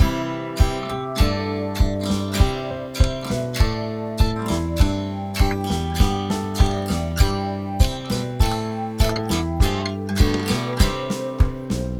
童話系カントリーバンド風BGM。(ループ)